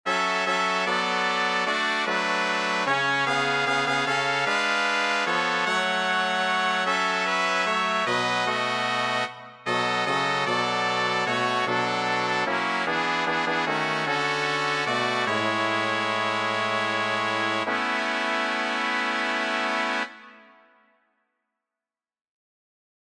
Key written in: A Major
How many parts: 4
Type: SATB
All Parts mix: